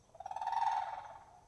Contact Call | A soft, purring call expressing reassurance and location.
Eurasian-Crane-Contact.mp3